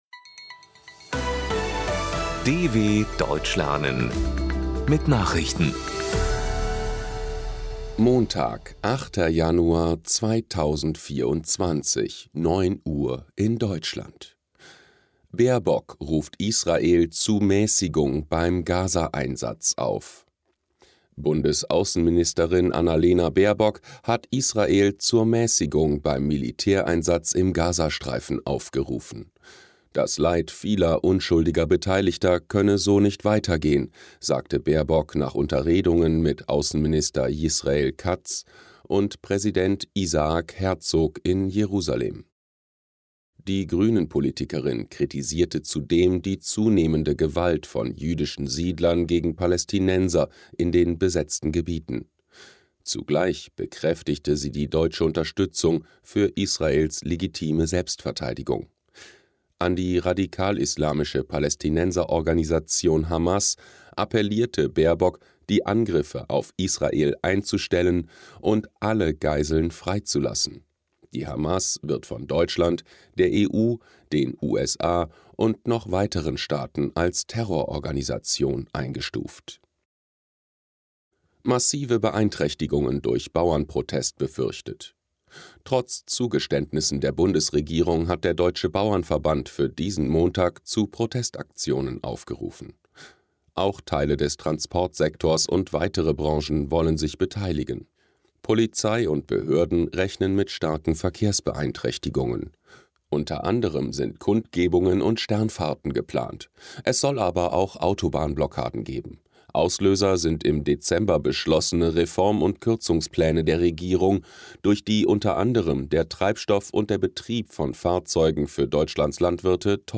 08.01.2024 – Langsam Gesprochene Nachrichten
Trainiere dein Hörverstehen mit den Nachrichten der Deutschen Welle von Montag – als Text und als verständlich gesprochene Audio-Datei.